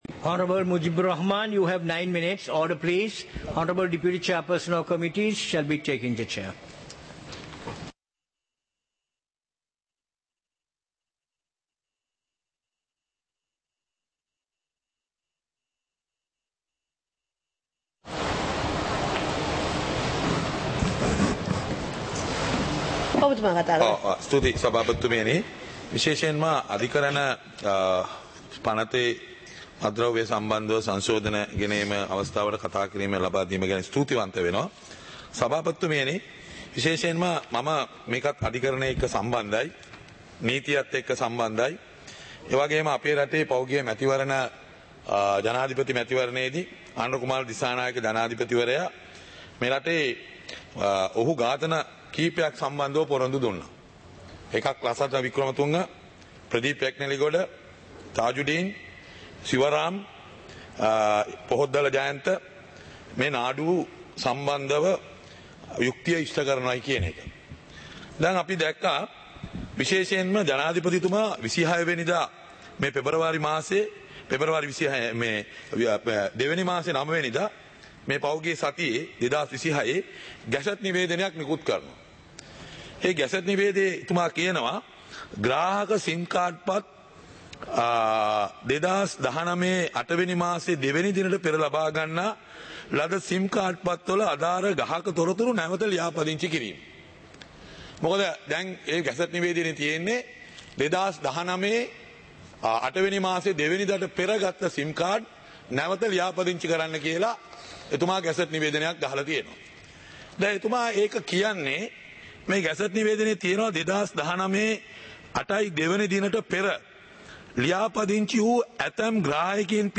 சபை நடவடிக்கைமுறை (2026-02-19)
நேரலை - பதிவுருத்தப்பட்ட